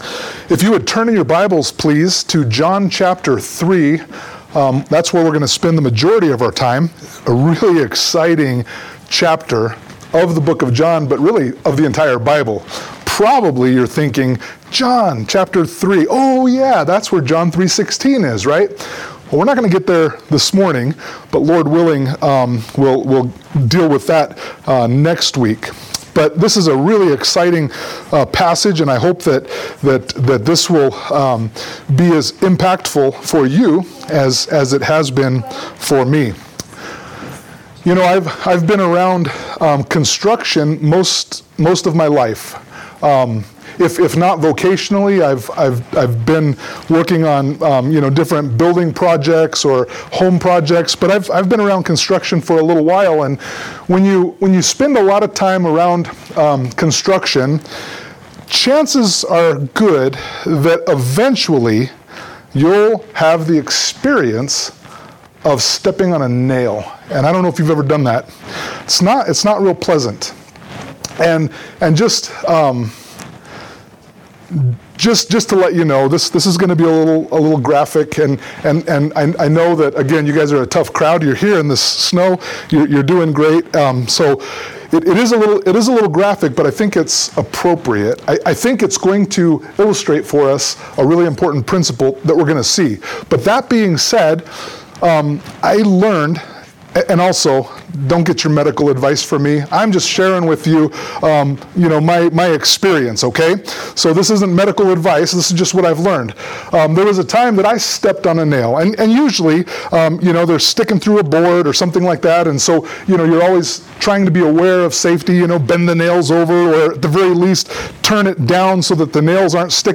John 3:1-15 Service Type: Sunday Morning Worship « John 2:13-25